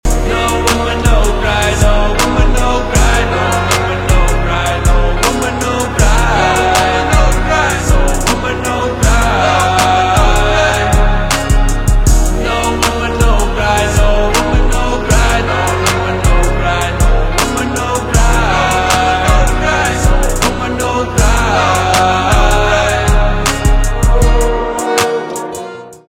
русский рэп
басы , гитара